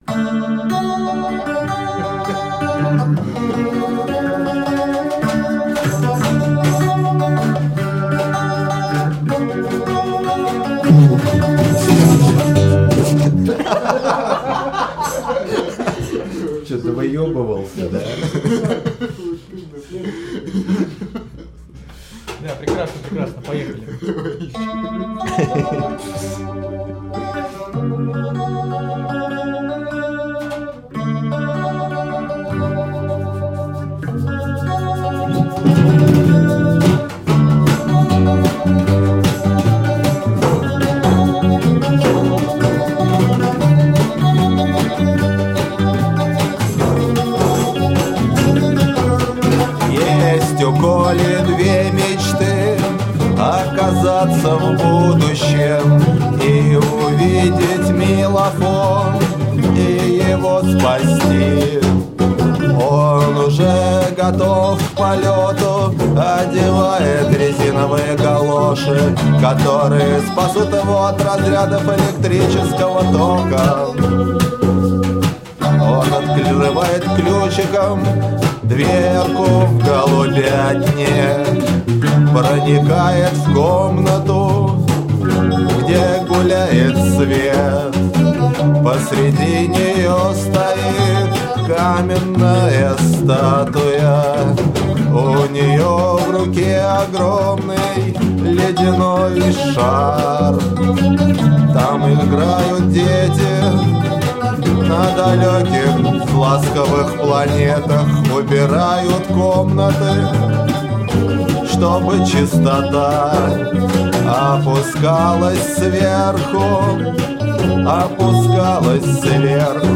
вокал.
укулеле, бас, гитара.
домбра, перкуссия, укулеле